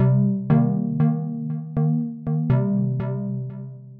Under Cover (5th Lead) 120BPM.wav